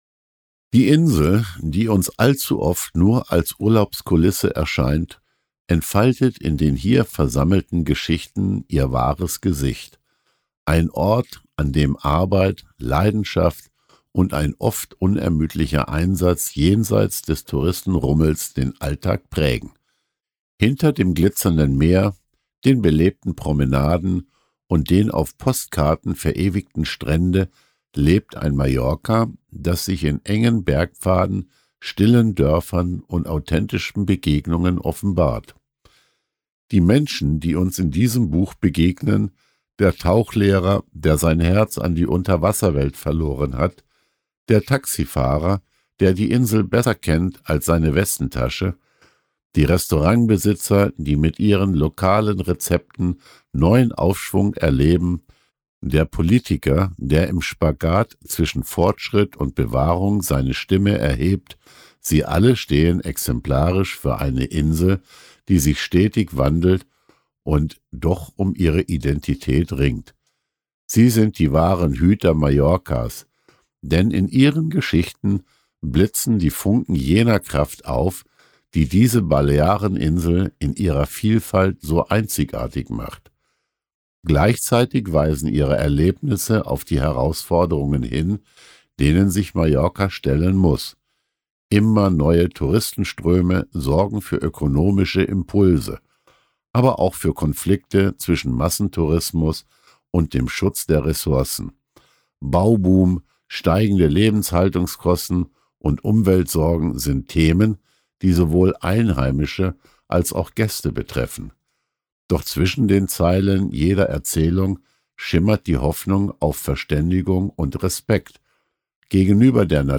Die Sprecherstimme trägt diese Atmosphäre mit Wärme und Ruhe und lässt die Geschichten wie kleine Inselreisen wirken – nah, greifbar und voll leiser Schönheit.
Jenseits-von-Sonne-und-Strand-Kurzgeschichten-ueber-Mallorca-Hoerprobe.mp3